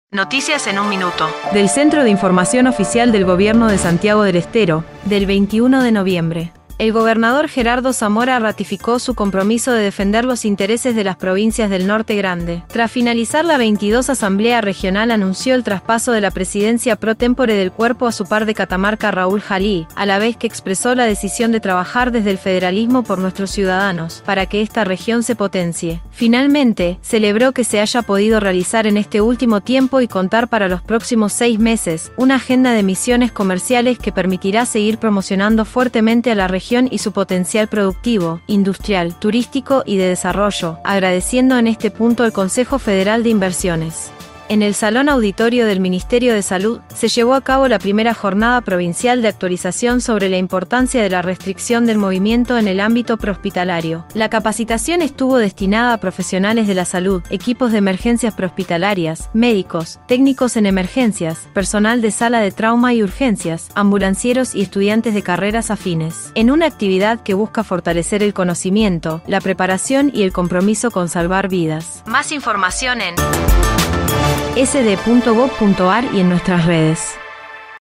En 1 minuto el reporte de hoy